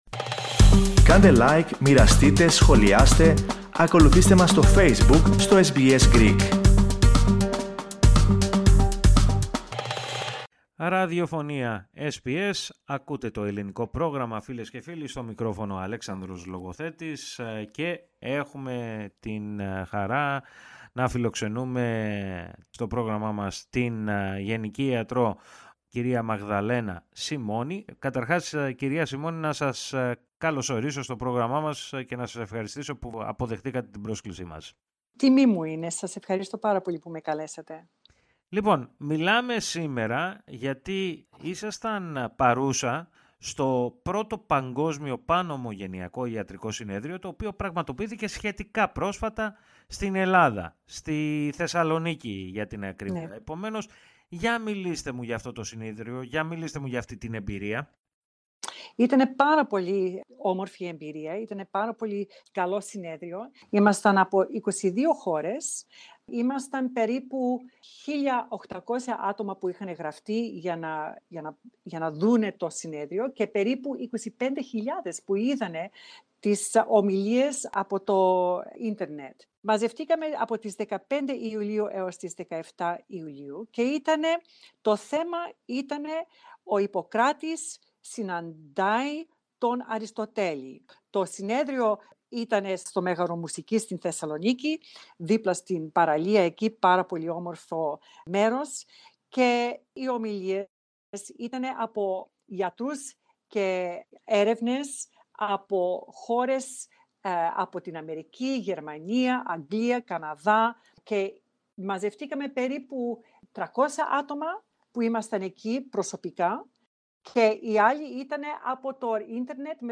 Ακούστε, όμως, ολόκληρη τη συνέντευξη πατώντας play, πάνω από την κεντρική φωτογραφία.